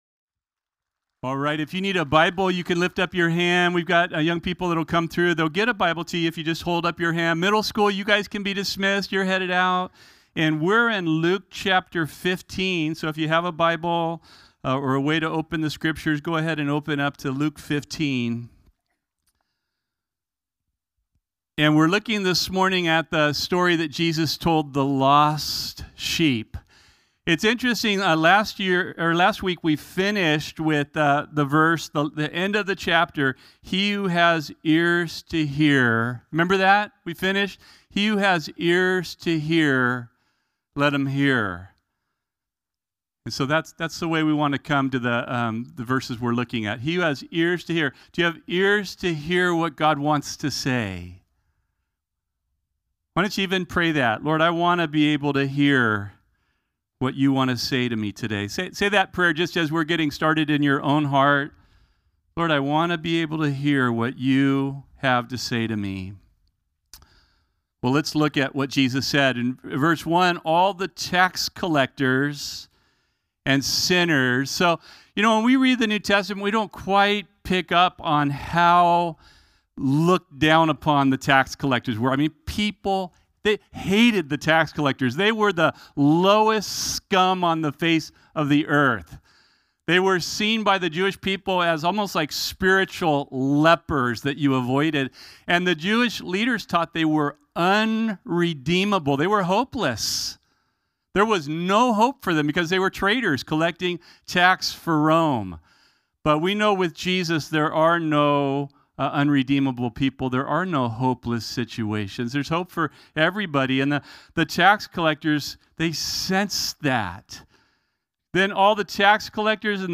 Bible studies given at Calvary Corvallis (Oregon).